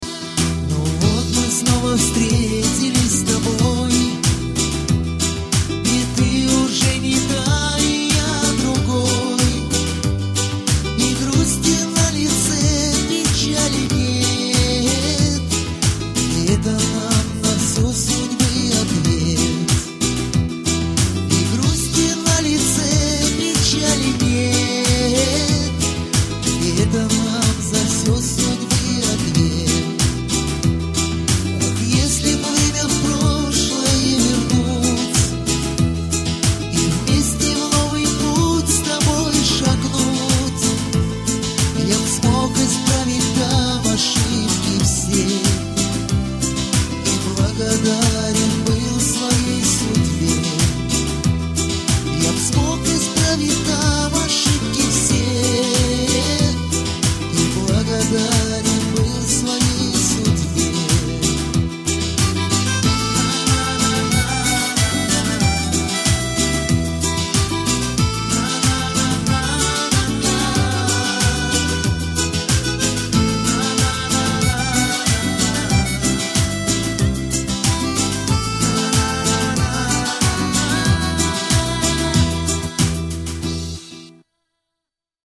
шансон рингтоны